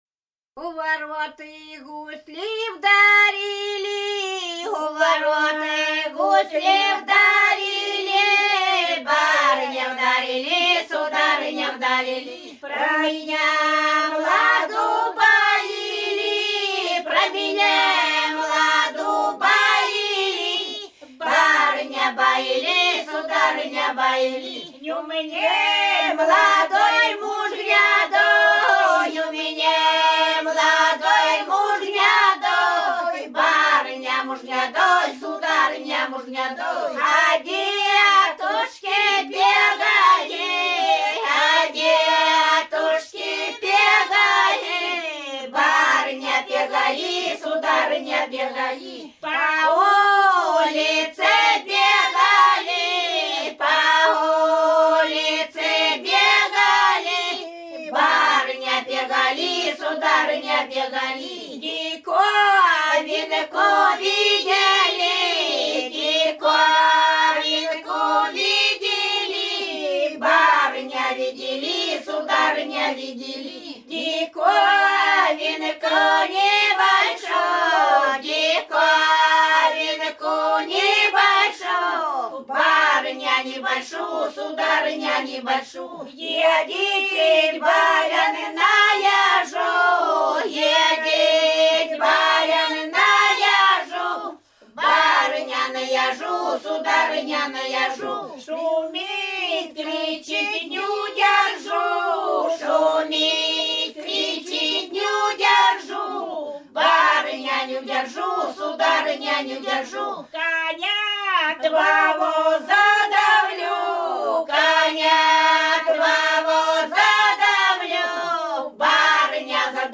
Рязань Кутуково «У ворот гусли вдарили», плясовая.